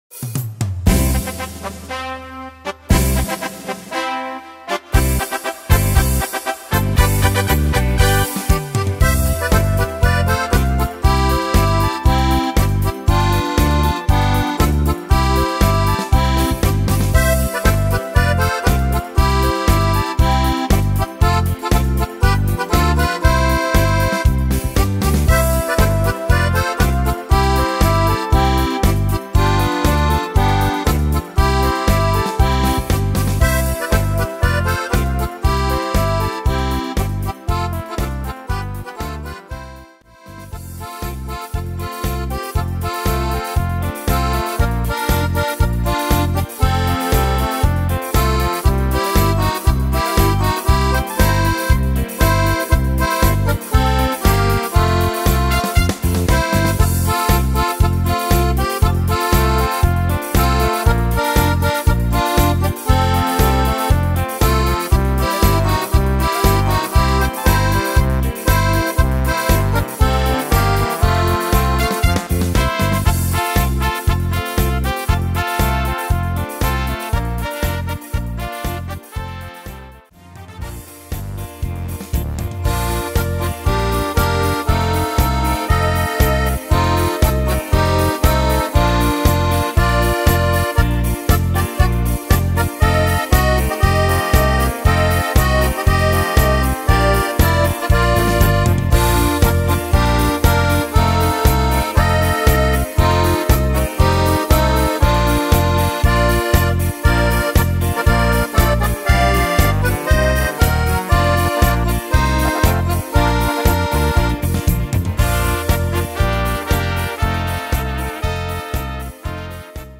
Tempo: 236 / Tonart: F-Dur